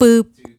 Boo-A#.wav